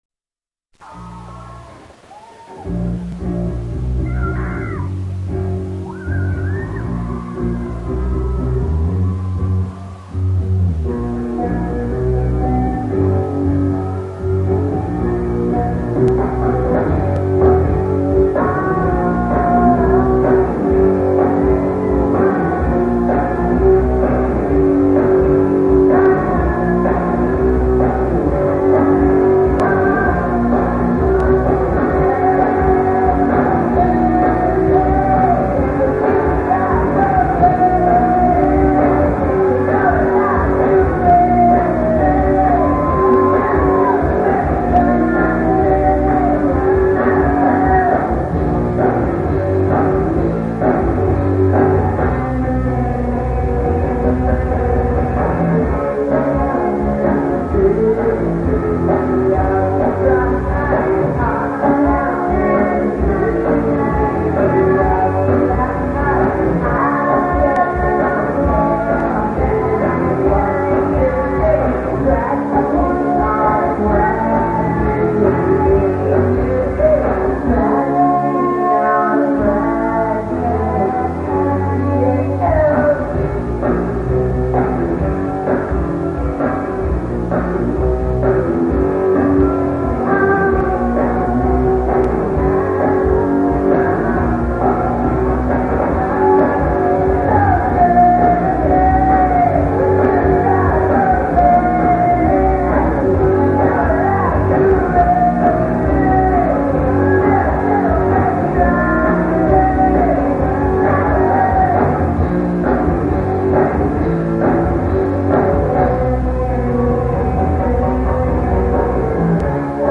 live 1998-1999
Enmore Theater, Sydney Australia